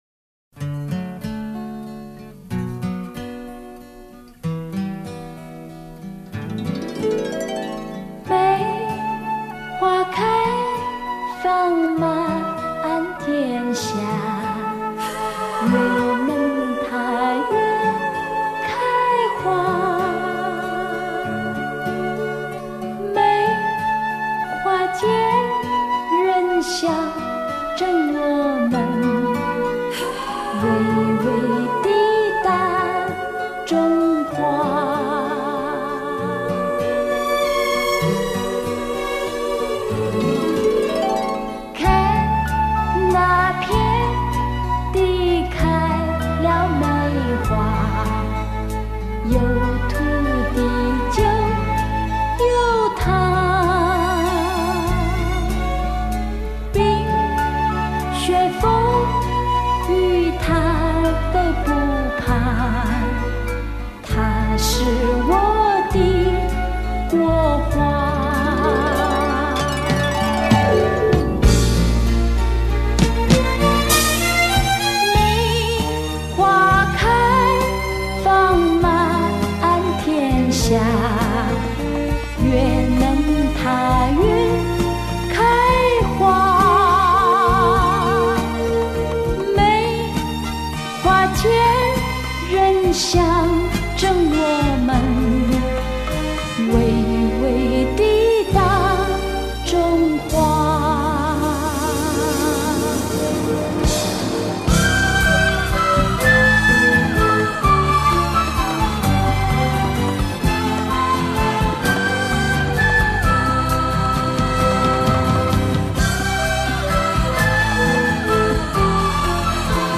●本专辑录音采用dcs 954机，经24bit Format特别处理
加上128倍超频取样，呈现最精密细致的音频讯号CD录音